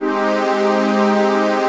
CHRDPAD030-LR.wav